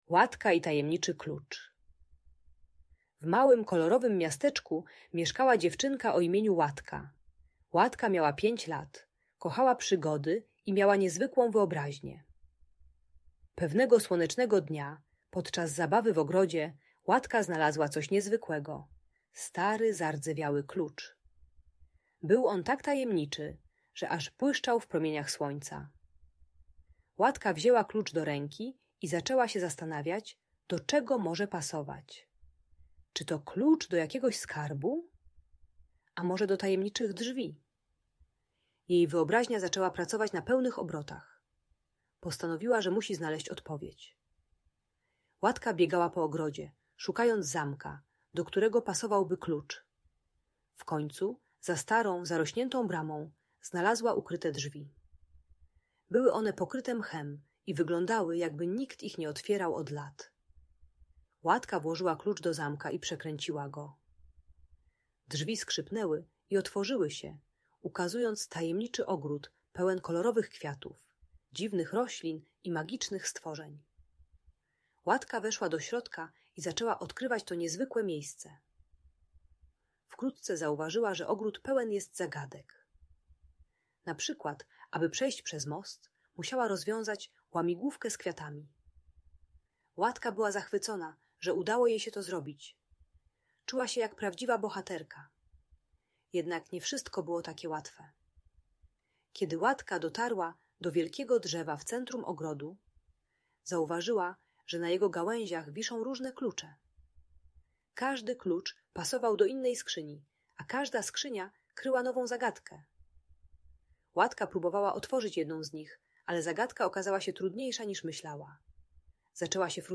Historia Łatki i Tajemniczego Klucza - Bunt i wybuchy złości | Audiobajka